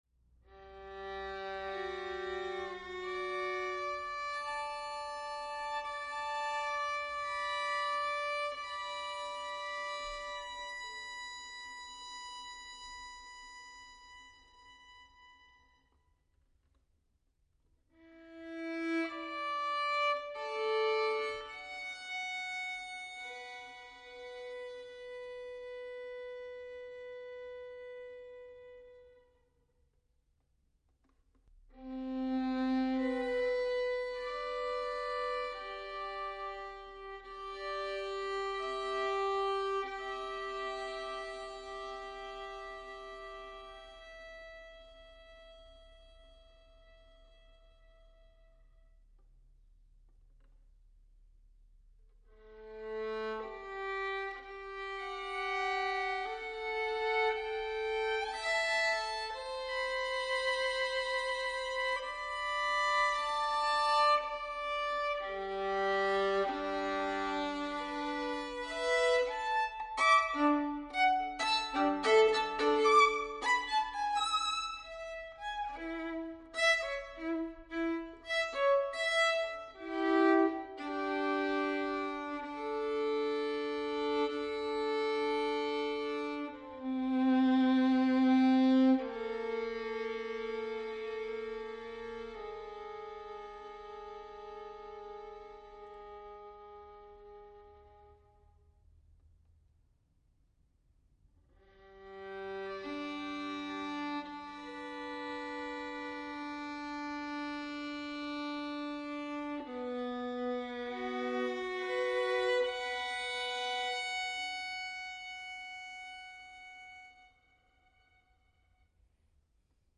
UNEDITED outtake.